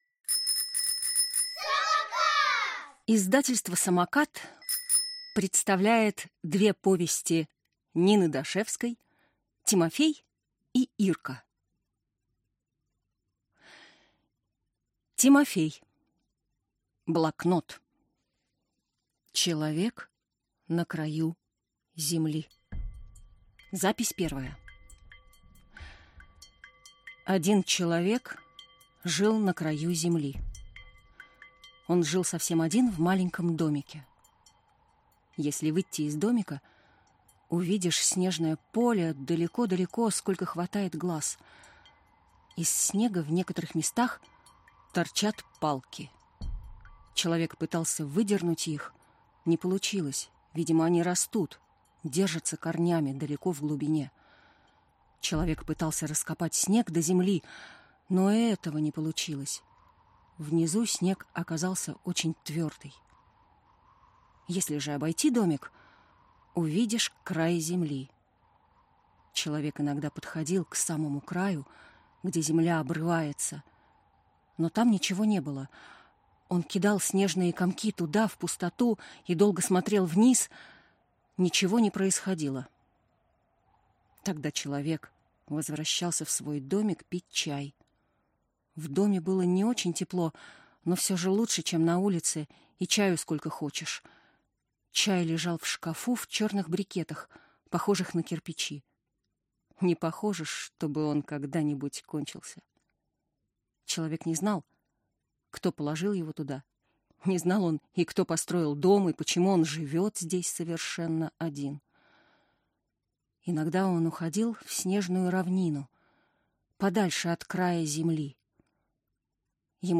Аудиокнига Тимофей: блокнот. Ирка: скетчбук | Библиотека аудиокниг